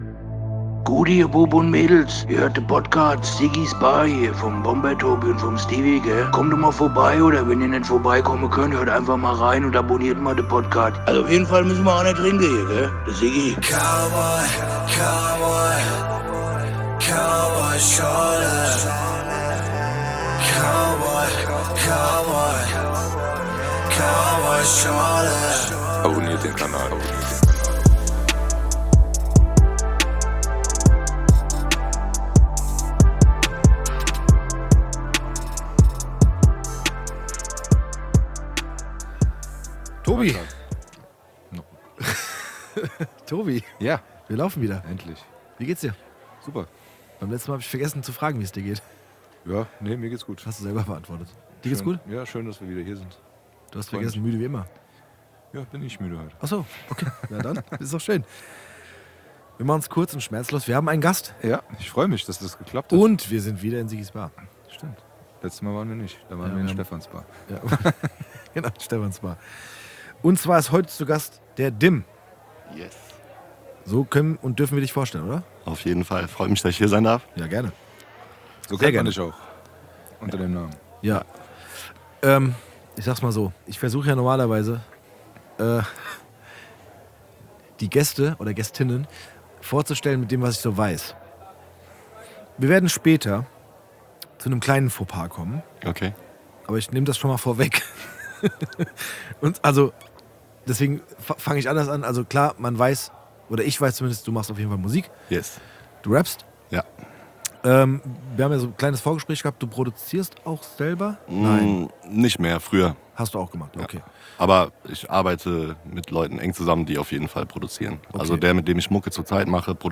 Ein kurzweiliges Gespräch, zumindest für die Protagonisten, denn die Zeit verging wie im Flug.